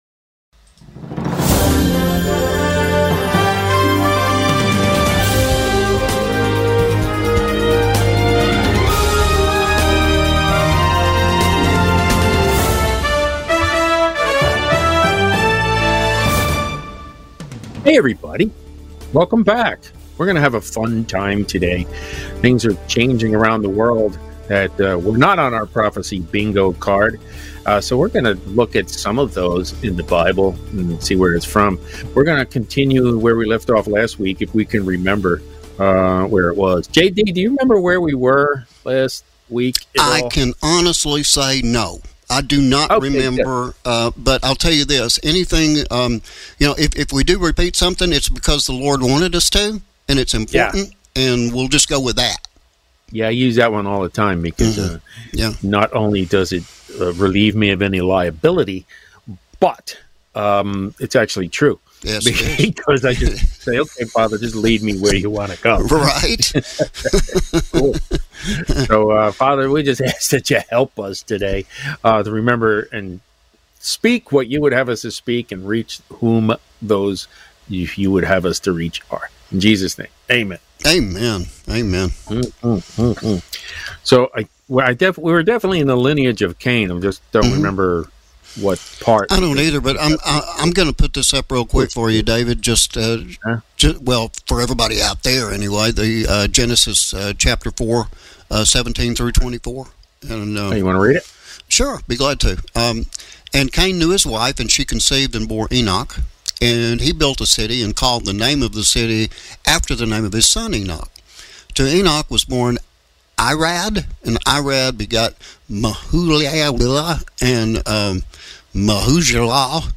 Our Weekly Comprehensive Bible Study